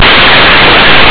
It is a single channel (mono) audio clip running at 8000Hz and compressed at 33kbps, however whatever created it has tagged the codec required as DEVELEOPMENT.
as an 8kHz-sampled mono bytestream and simply saved the result as